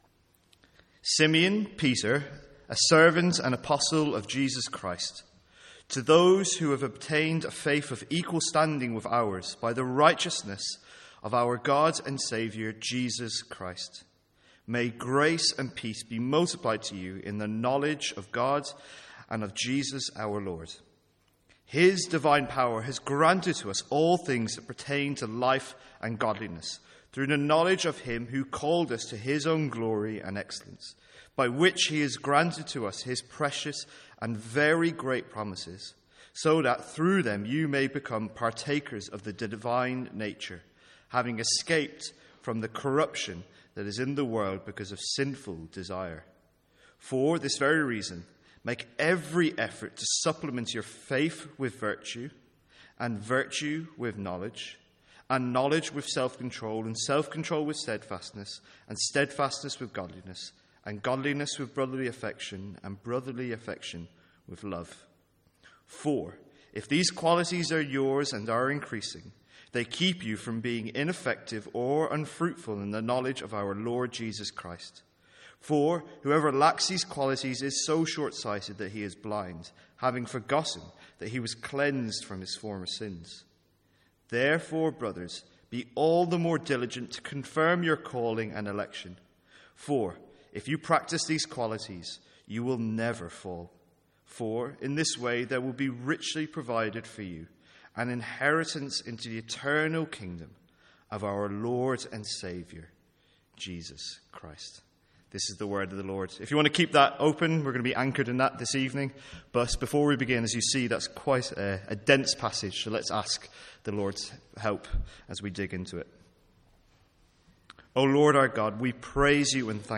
Sermons | St Andrews Free Church
From our evening series in 2 Peter.